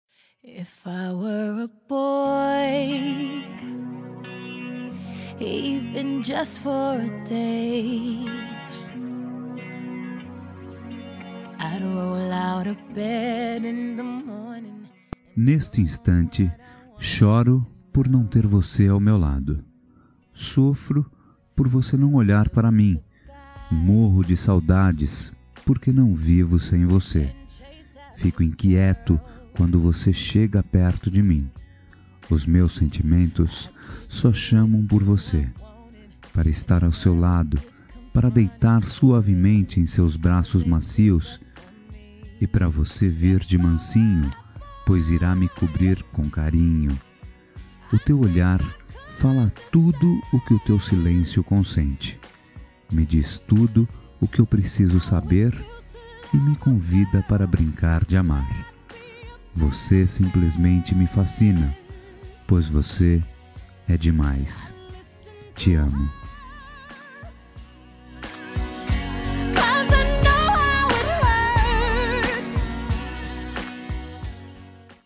Dia Dos Namorados Voz Masculina